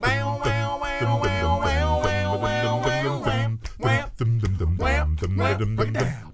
ACCAPELLA10A.wav